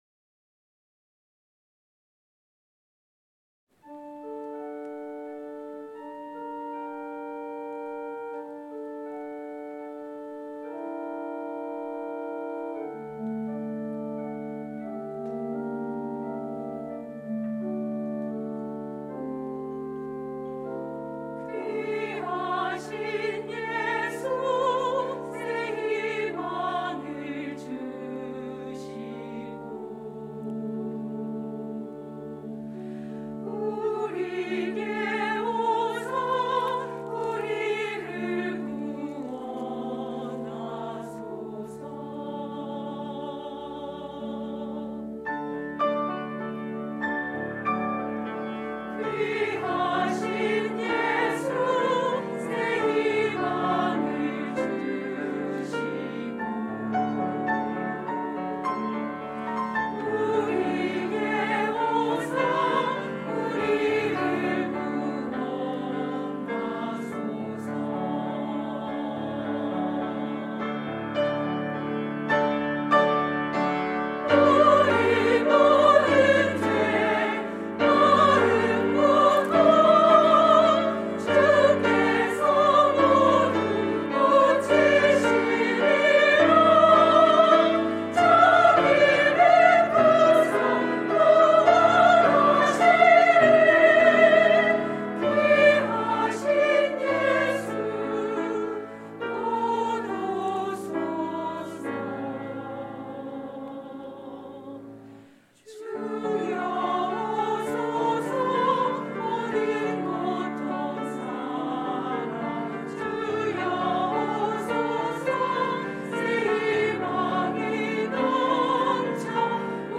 샤론